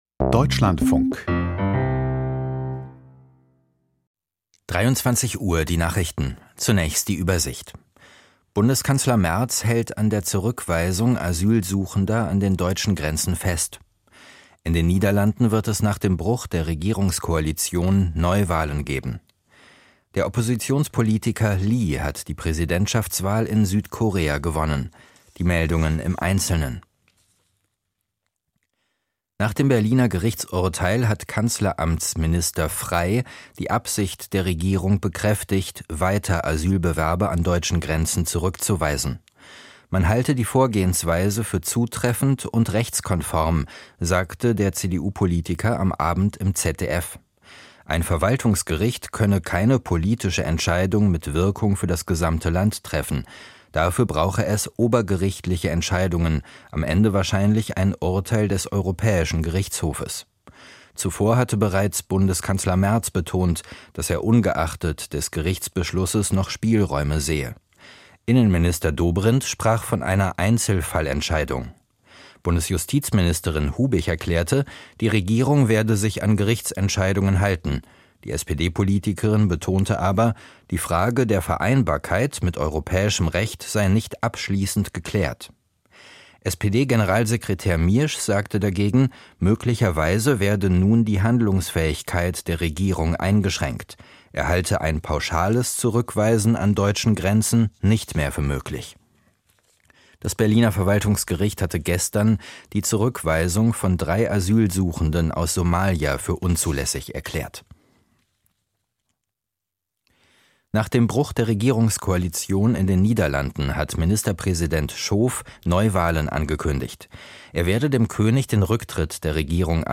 Die Nachrichten vom 03.06.2025, 23:00 Uhr